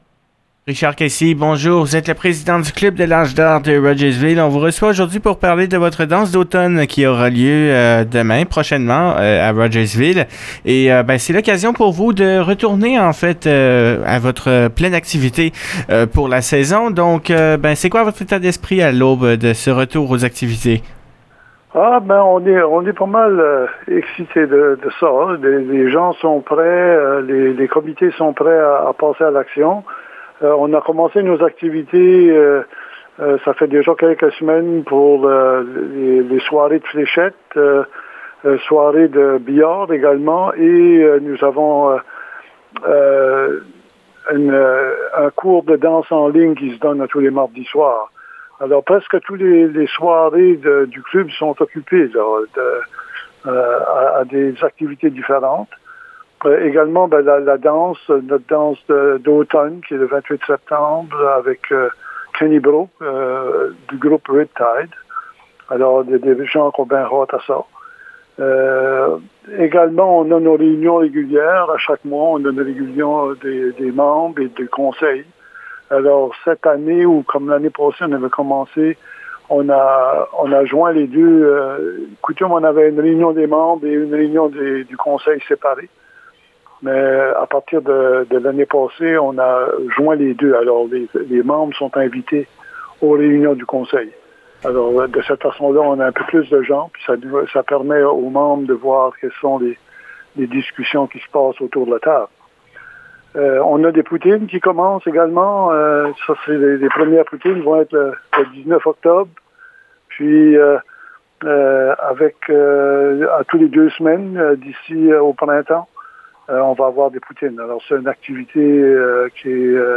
Une entrevue